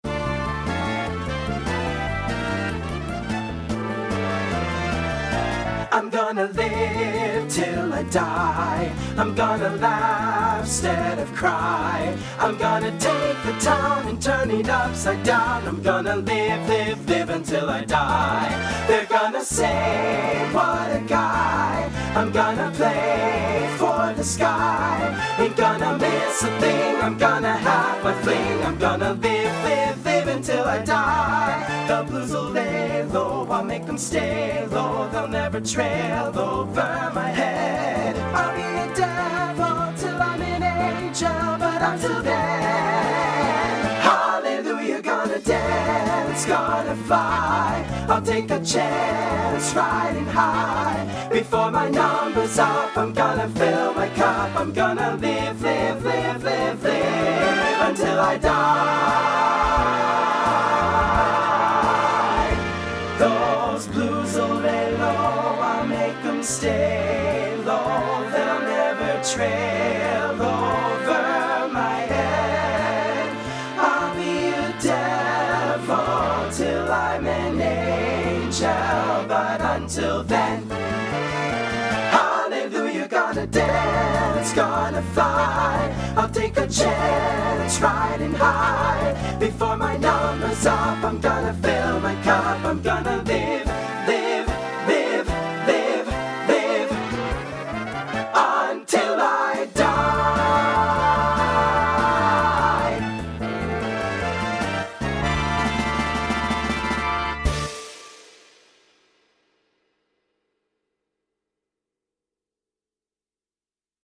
Voicing SATB Instrumental combo Genre Swing/Jazz